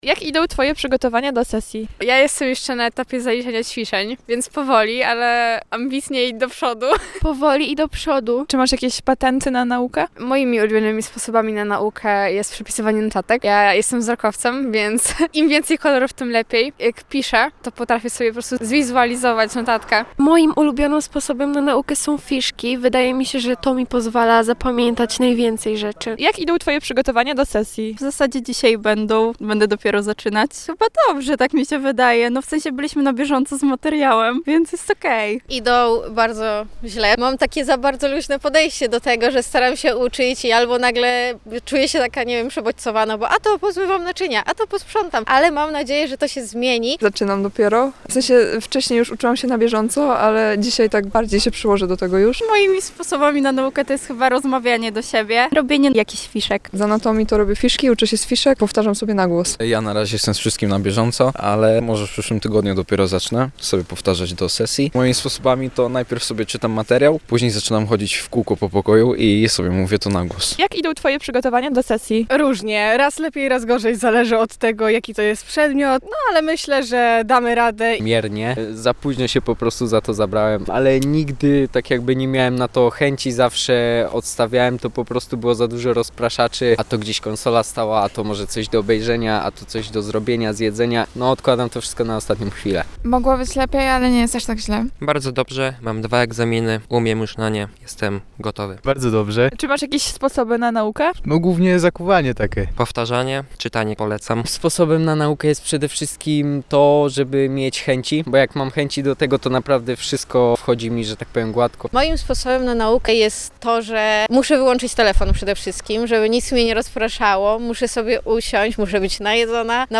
2001-MA-studenci-o-przygotowaniach-do-sesji-zimowej.mp3